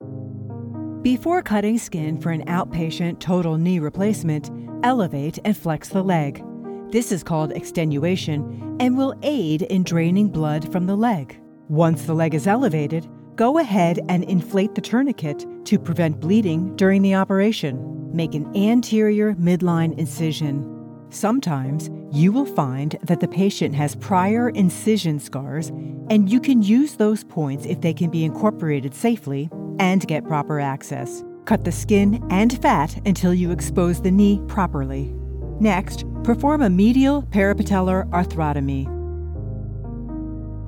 medical, trustworthy, confident, clear, polished, engaging
Dr Elearning with music GOOD_mixdown.mp3